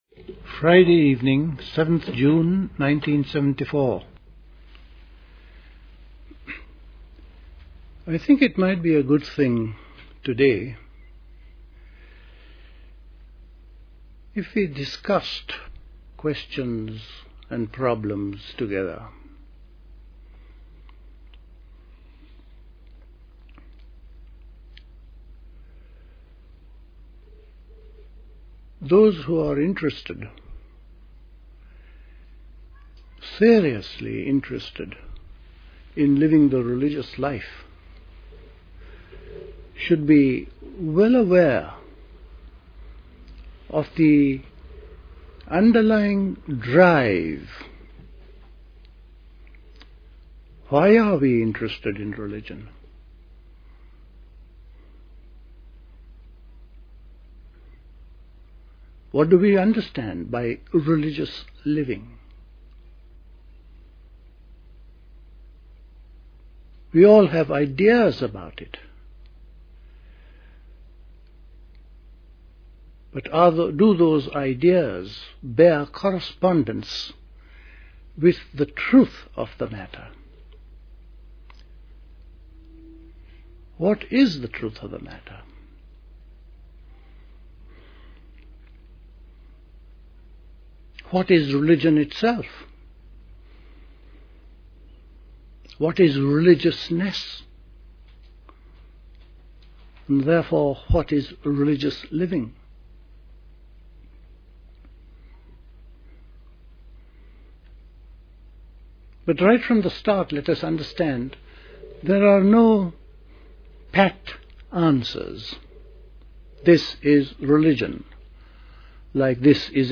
A talk
at Dilkusha, Forest Hill, London on 7th June 1974